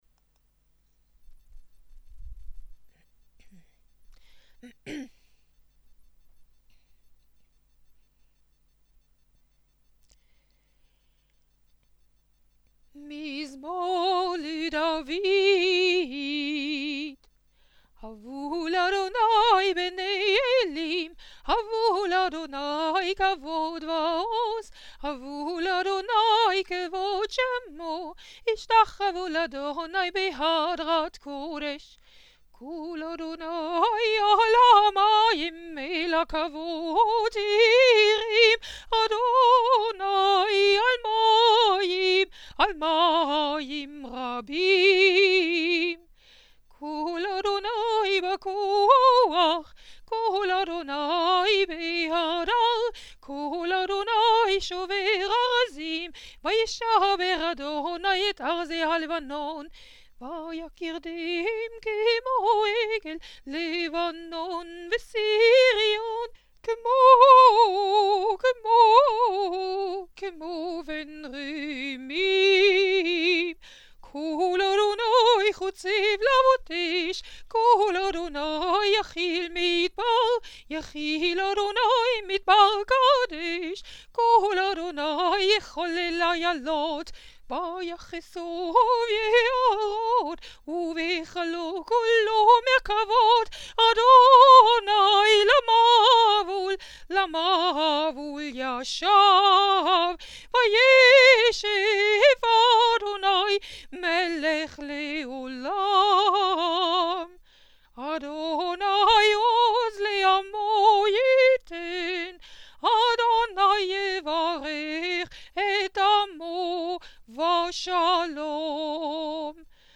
Mizmor leDavid (p. 164 [pdf 171]) / procession, congregational tune